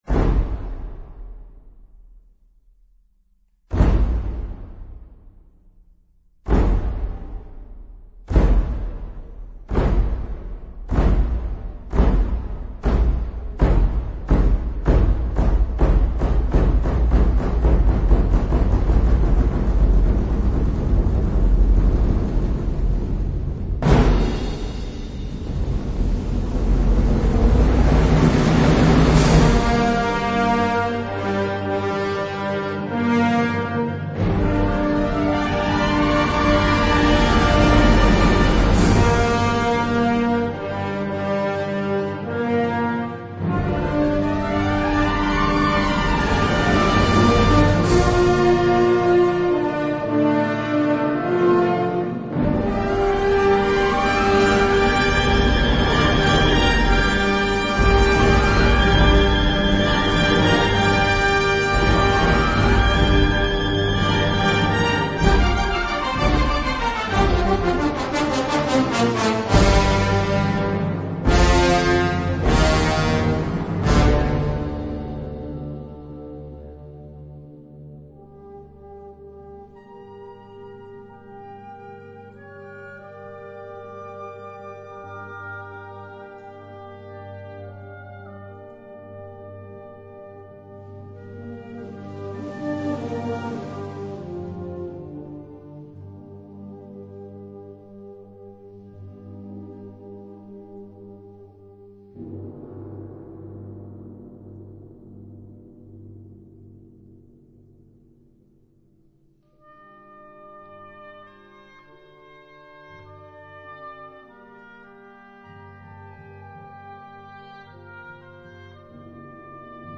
Kategorie Blasorchester/HaFaBra
Unterkategorie Zeitgenössische Musik (1945-heute)
Besetzung Ha (Blasorchester)